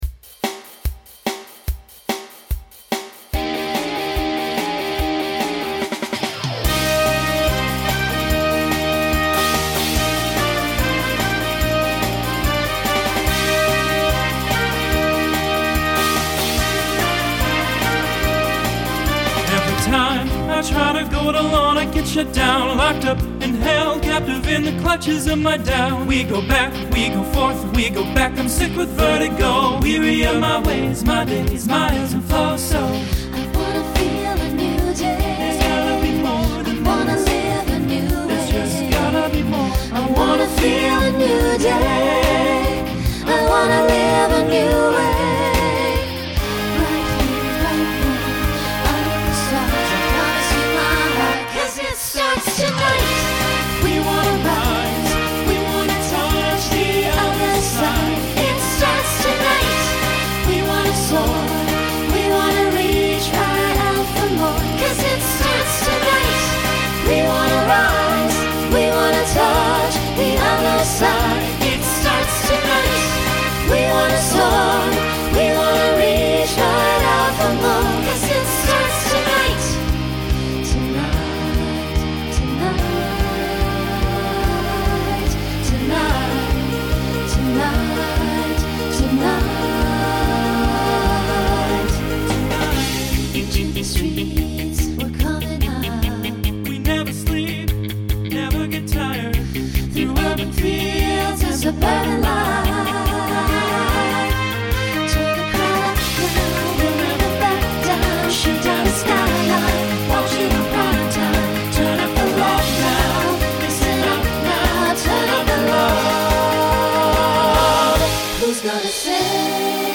Voicing SATB Instrumental combo Genre Pop/Dance , Rock